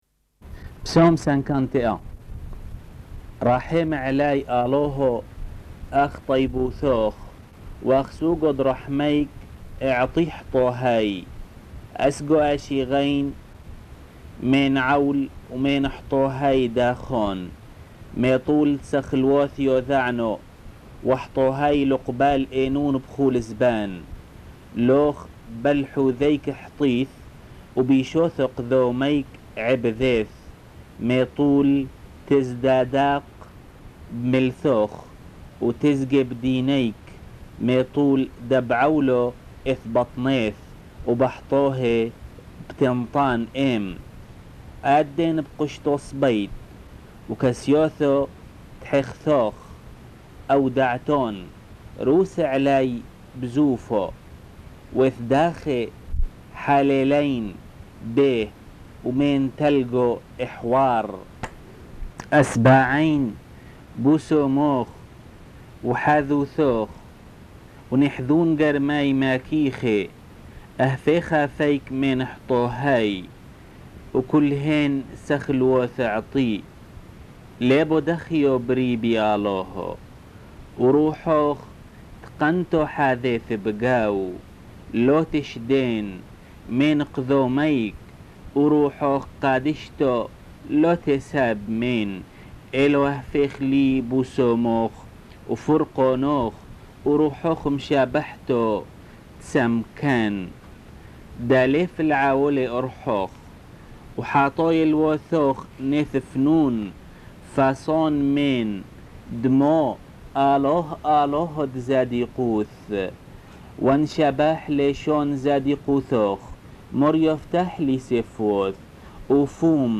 Enregistrement de la lecture des Psaumes